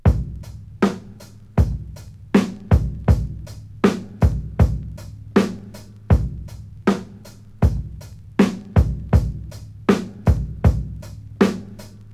• 79 Bpm Drum Loop C Key.wav
Free drum beat - kick tuned to the C note. Loudest frequency: 469Hz
79-bpm-drum-loop-c-key-6Sa.wav